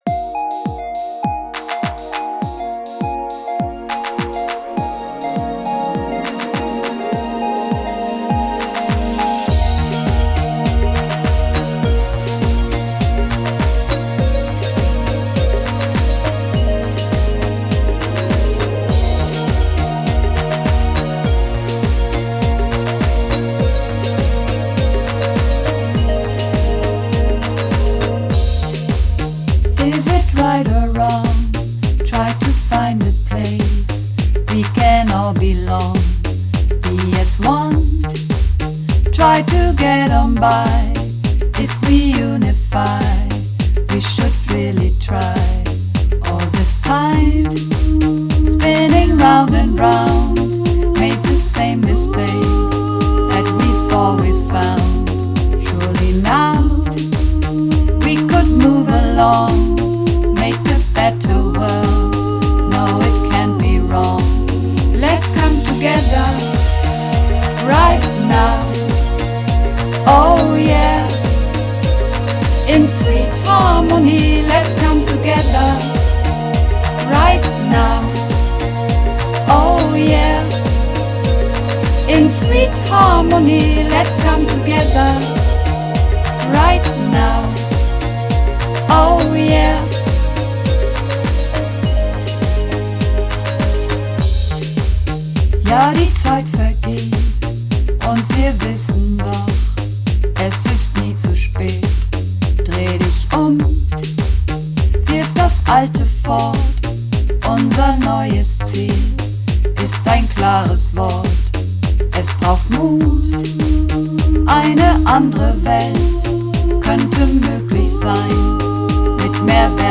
Übungsaufnahmen - Sweet Harmony
Sweet Harmony (Mehrstimmig)